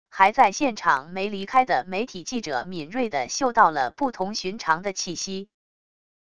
还在现场没离开的媒体记者敏锐地嗅到了不同寻常的气息wav音频生成系统WAV Audio Player